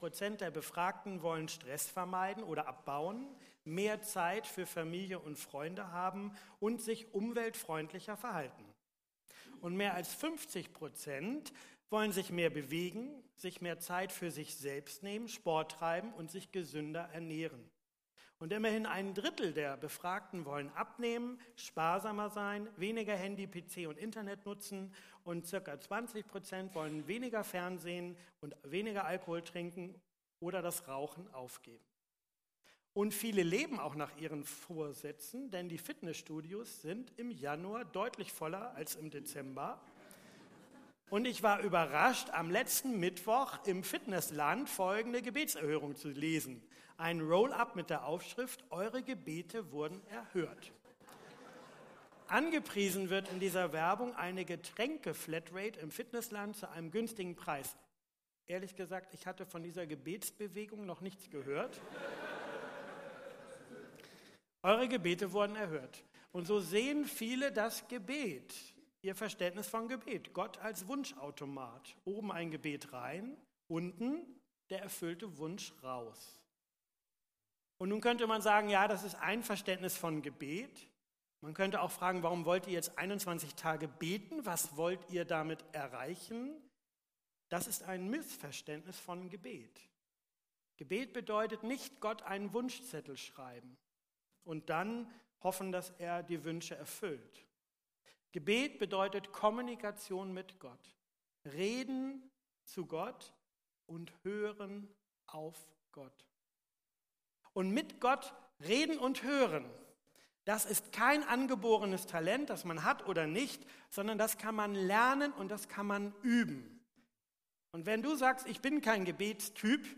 Predigttext: Matthäus 4,1-4; 5.Mose 8,1-14